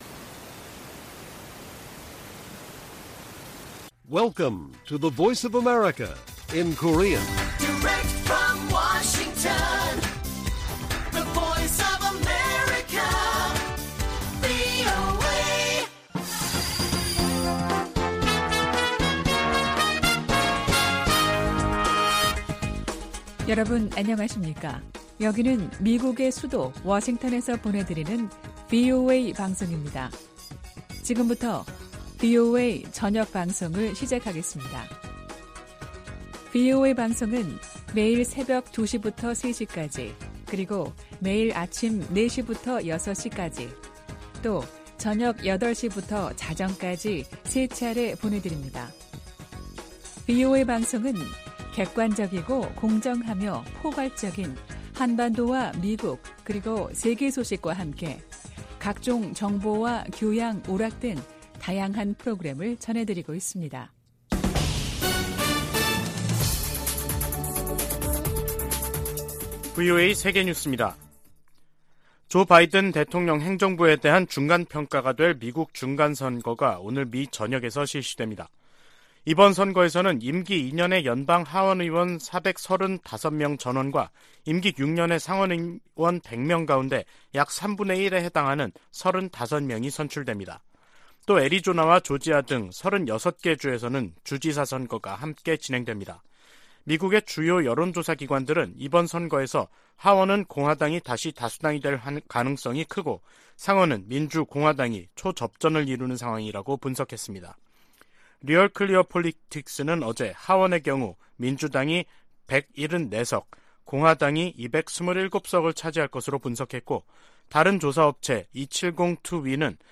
VOA 한국어 간판 뉴스 프로그램 '뉴스 투데이', 2022년 11월 8일 1부 방송입니다. 미국에서 임기 2년의 연방 하원의원 435명 전원과 임기 6년의 연방 상원의원 3분의 1을 선출하는 중간선거 투표가 실시되고 있습니다. 미 국무부는 유엔 안보리에서 북한에 대한 제재와 규탄 성명 채택을 막고 있는 중국과 러시아를 정면으로 비판했습니다. 유럽연합은 북한의 잇단 미사일 도발이 전 세계에 심각한 위협이라며 국제사회의 단합된 대응을 촉구했습니다.